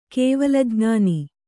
♪ kēvala jñāni